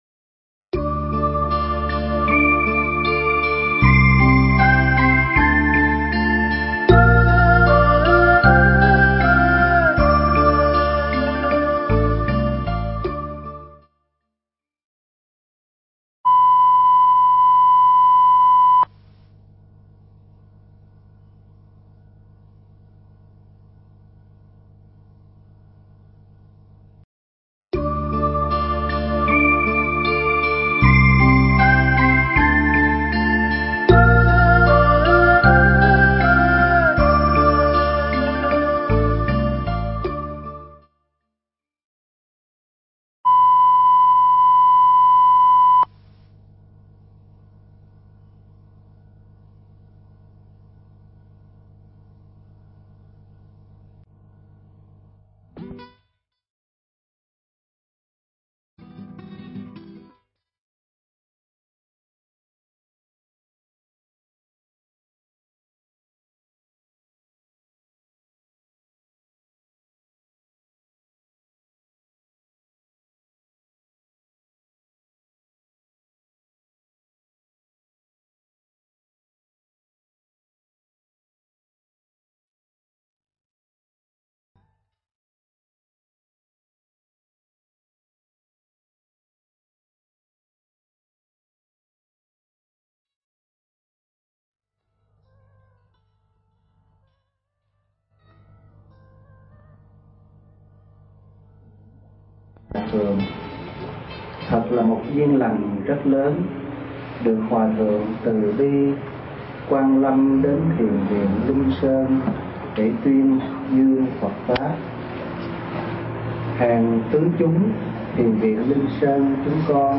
Mp3 Pháp Thoại Đạo Phật Rất Công Bằng Và Bình Đẳng – Hòa Thượng Thích Thanh Từ